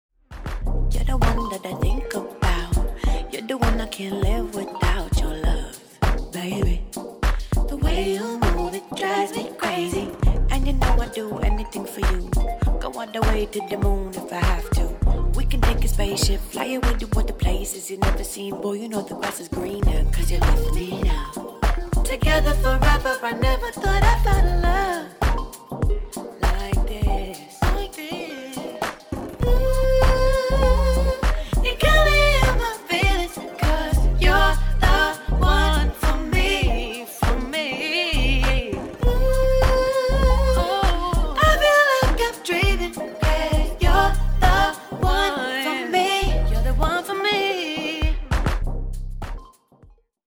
Rap